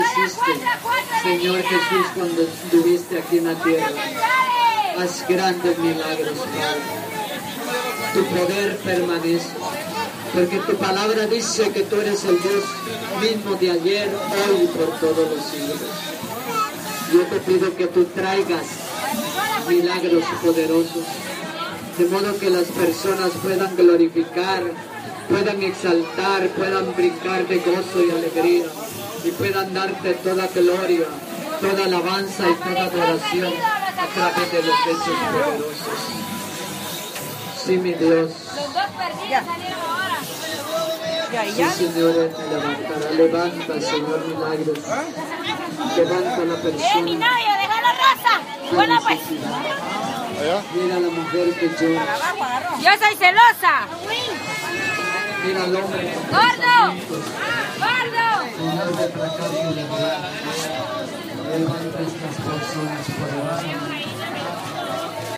En dat allemaal overschreeuwd door uit de kluiten gewassen marktvrouwen, en door opzwepende predikanten door dikke luidsprekers.
Geweldig die prediker, onverstoorbaar.
Mercado-Escuintla.mp3